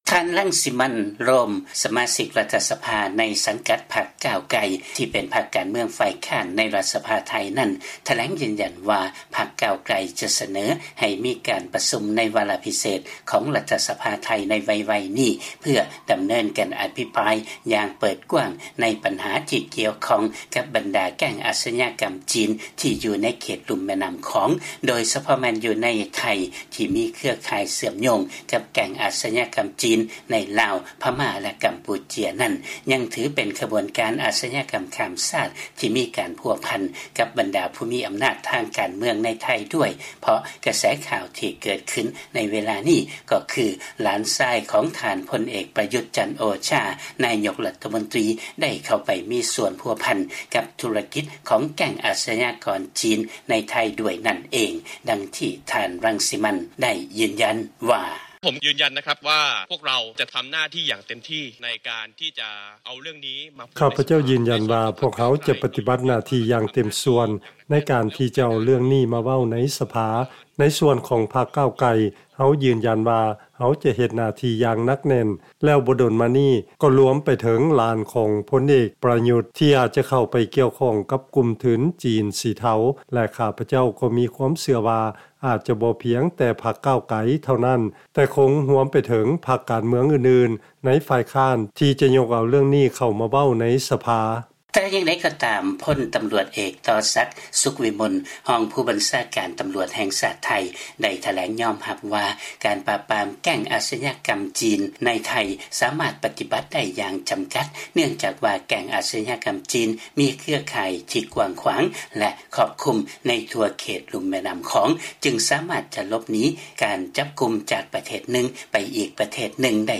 ຟັງລາຍງານ ສະມາຊິກລັດຖະສະພາ ຈະທຳການກວດສອບ ນາຍົກໄທ ແລະຄົນໃກ້ຊິດ ວ່າ ມີສ່ວນກ່ຽວຂ້ອງກັບແກັ່ງອາຊະຍາກອນຈີນ ໃນລຸ່ມແມ່ນ້ຳຂອງ ຫຼືບໍ່